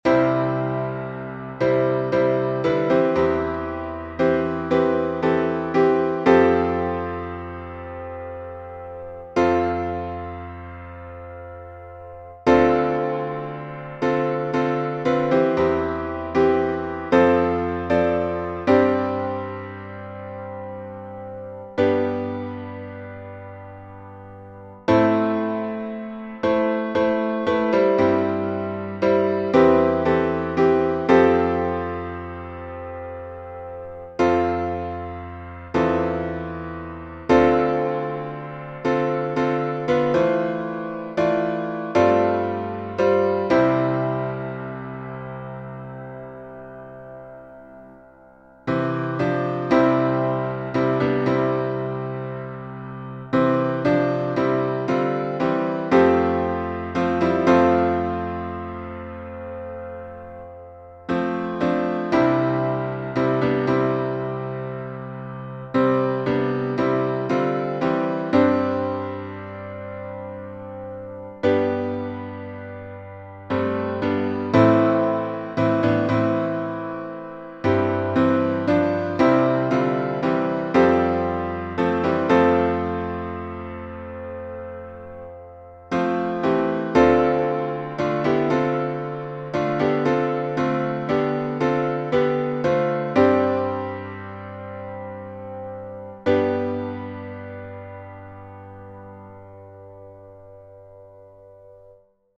Key signature: C major
Time signature: 6/8